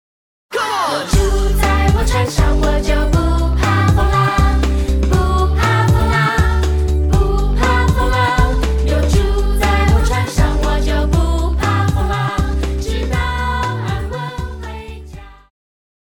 Christian
Pop chorus,Children Voice
Band
Hymn,POP,Christian Music
Voice with accompaniment